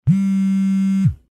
phone_ringing.mp3